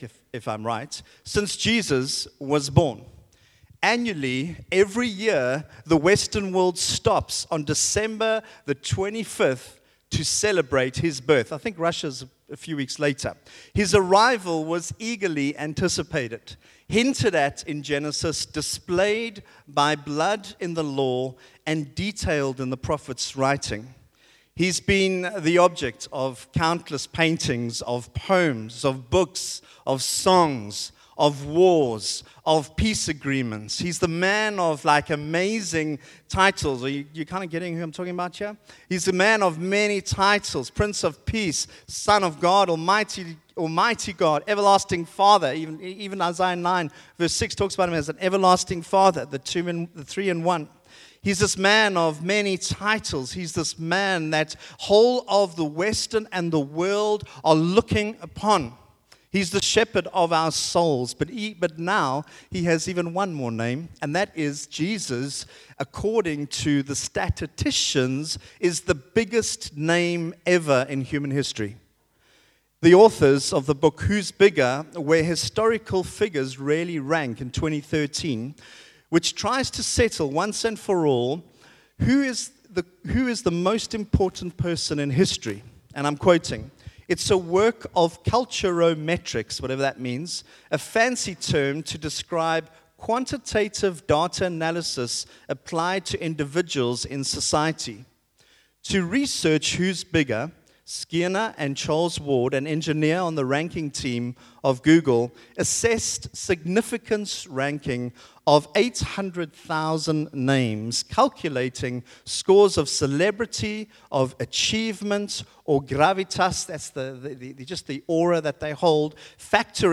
Sunday Service – 3 Dec
Sermons